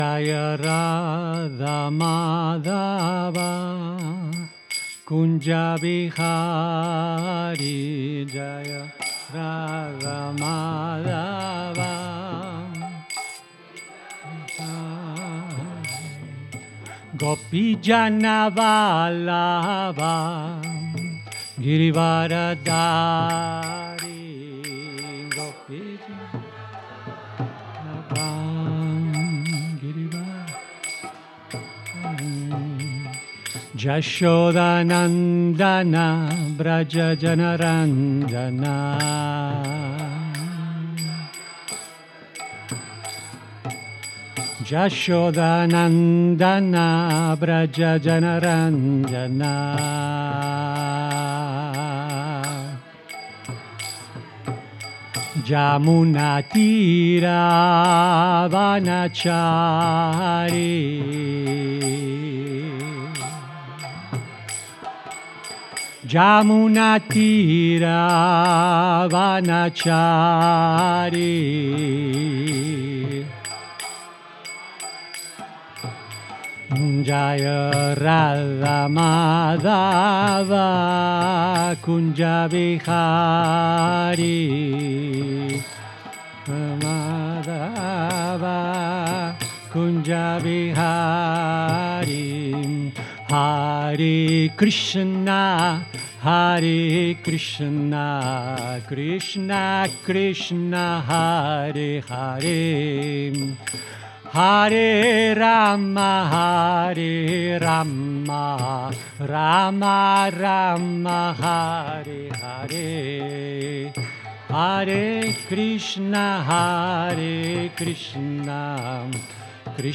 Lectures and kirtanas (devotional music) from the Hare Krishna temple in Alachua, Florida.